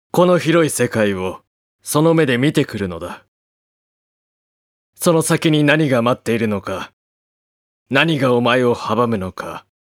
ボイス
性別：男
威厳のある物言いや的確な指示と、とても頼りになる存在。